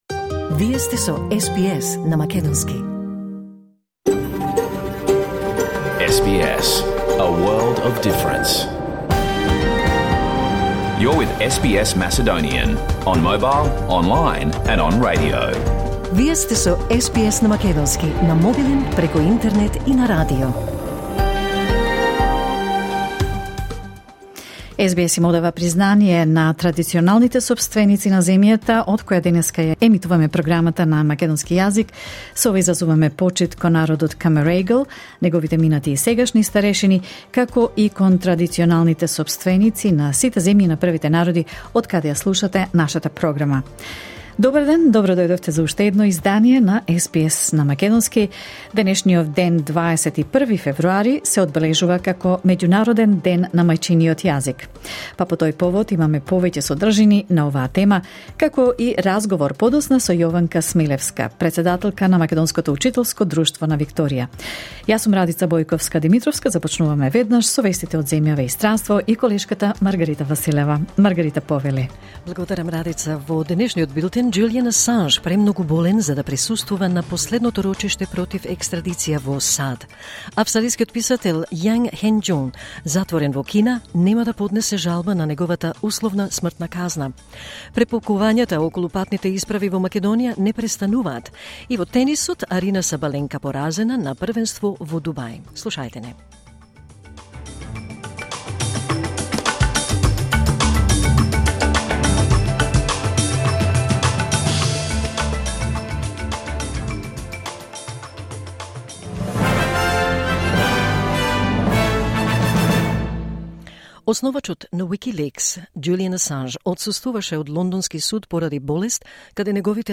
SBS Macedonian Program Live on Air 21 February 2024